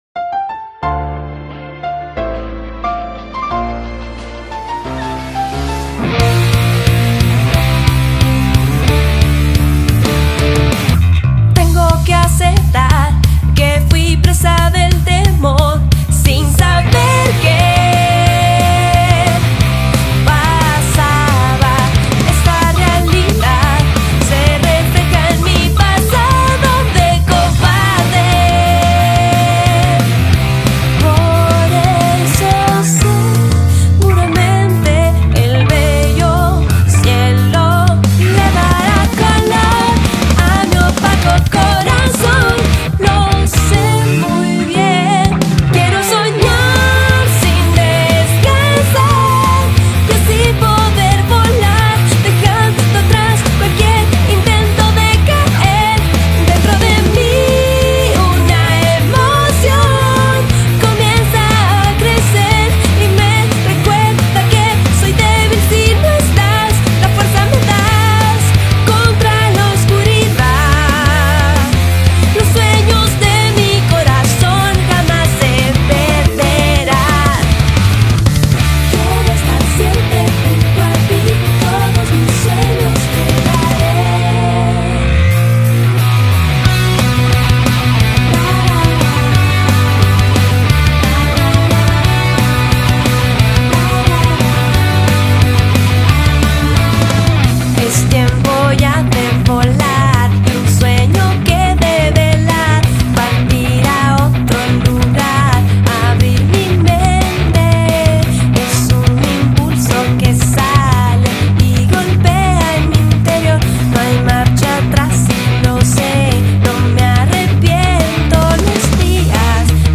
BPM82-330